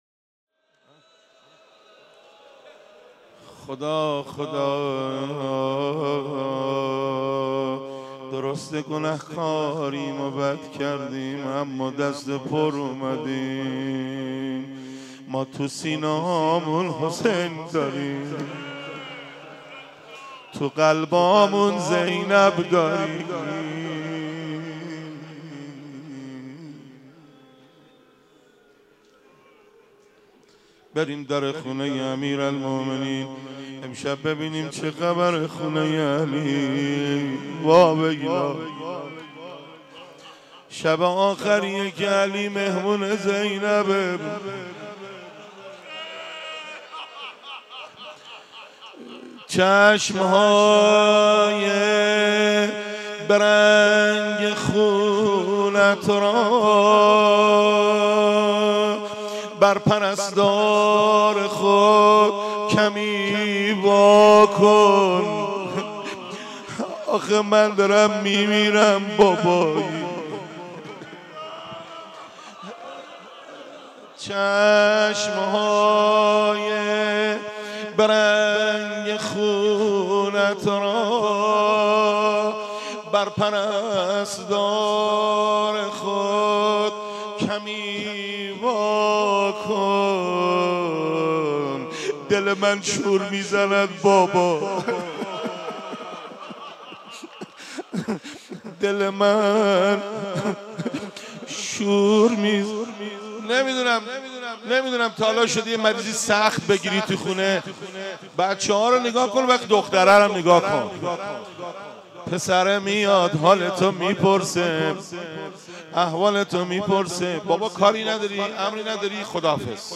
روضه و مناجات